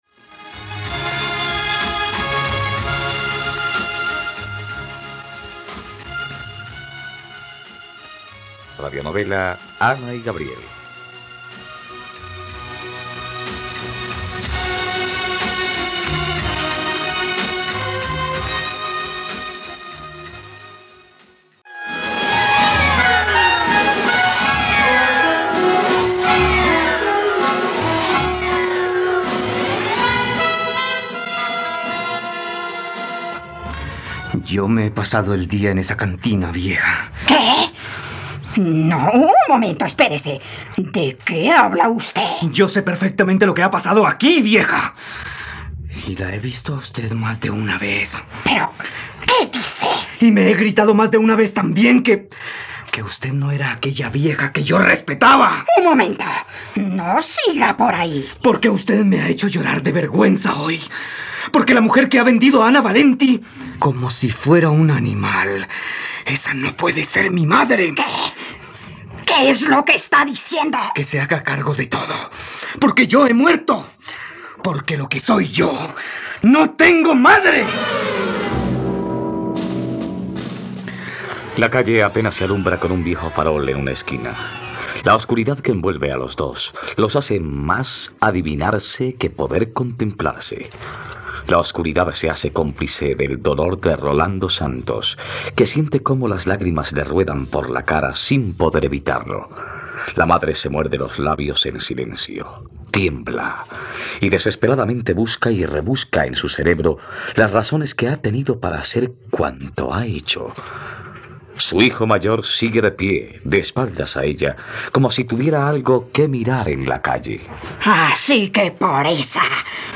..Radionovela. Escucha ahora el capítulo 125 de la historia de amor de Ana y Gabriel en la plataforma de streaming de los colombianos: RTVCPlay.